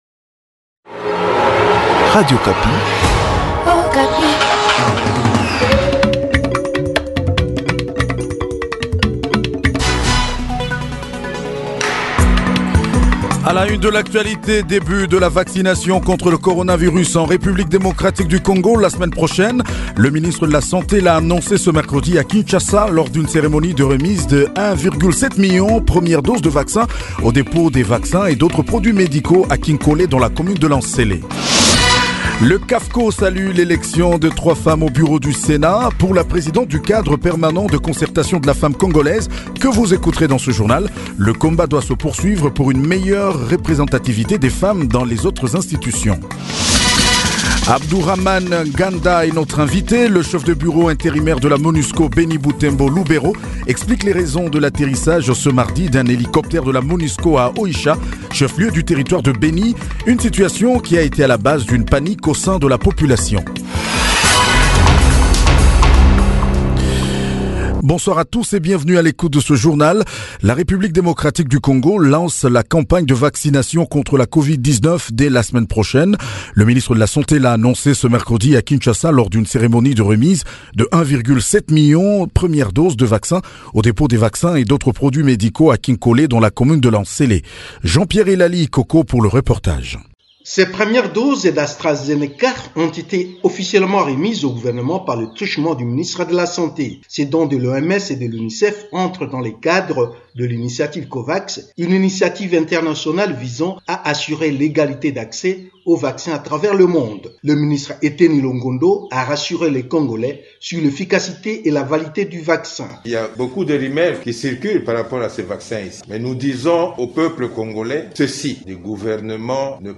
JOURNAL SOIR DU MERCREDI 03 MARS 2021